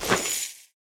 equip_chain1.ogg